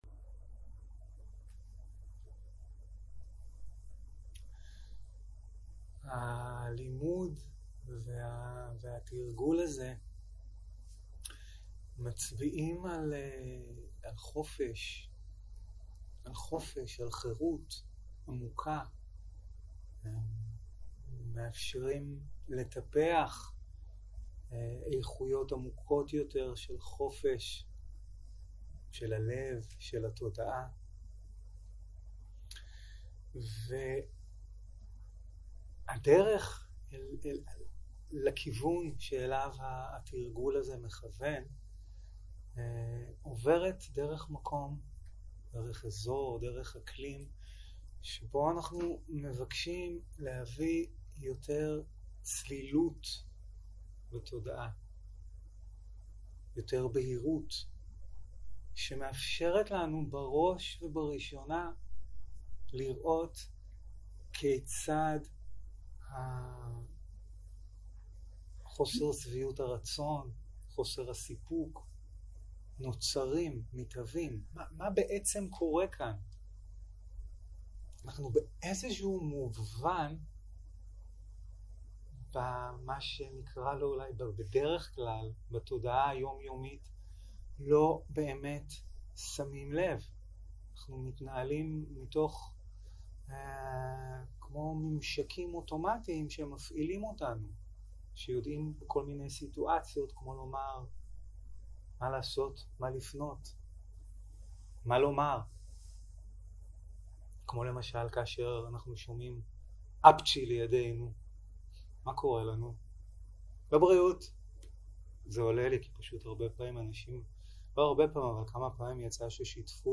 יום 2 - בוקר - הנחיות למדיטציה - הזמנה לחופש והנחיות למדיטציה בהליכה - הקלטה 2 0:00 0:00 סוג ההקלטה: Dharma type: Guided meditation שפת ההקלטה: Dharma talk language: Hebrew